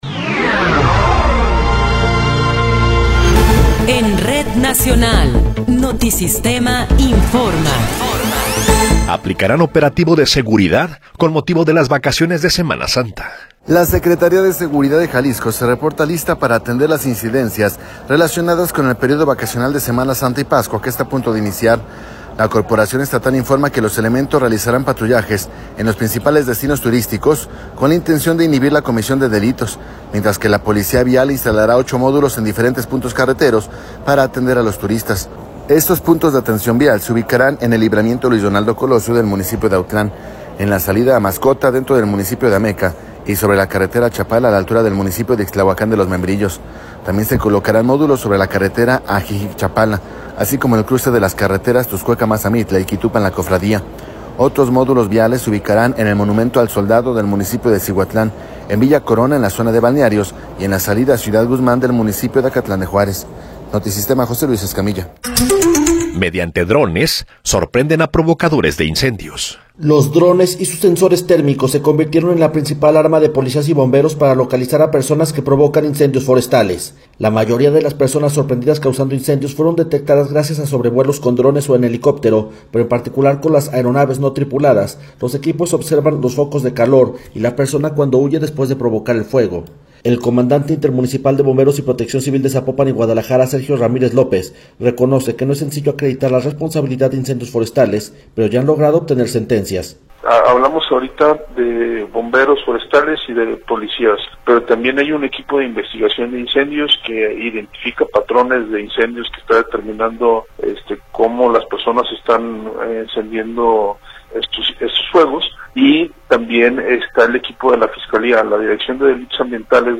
Noticiero 12 hrs. – 20 de Marzo de 2024
Resumen informativo Notisistema, la mejor y más completa información cada hora en la hora.